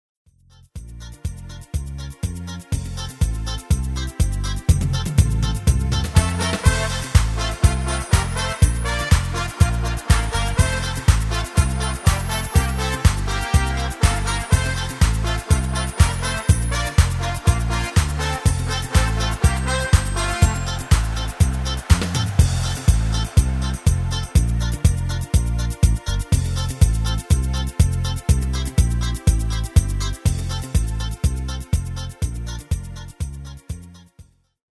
Demo/Koop midifile
Genre: Carnaval / Party / Apres Ski
- Géén vocal harmony tracks
Demo's zijn eigen opnames van onze digitale arrangementen.